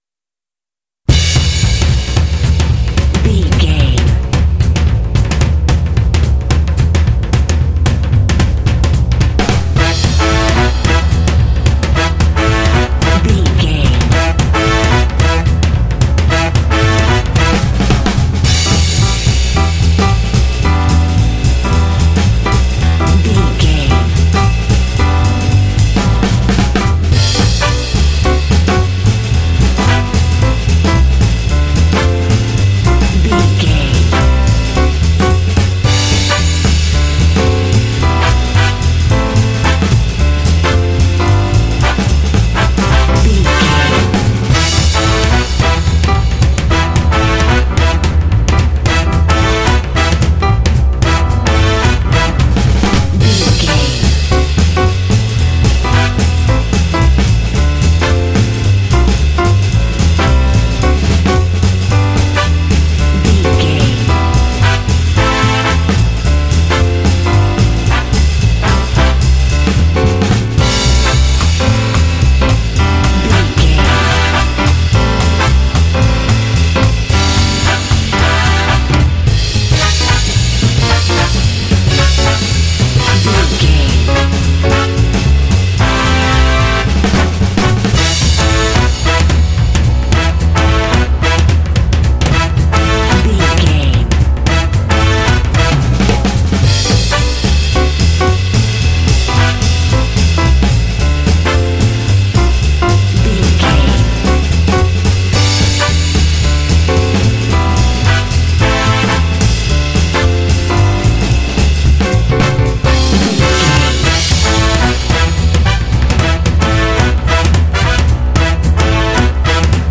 Uplifting
Aeolian/Minor
Fast
energetic
lively
cheerful/happy
drums
piano
brass
double bass
big band
jazz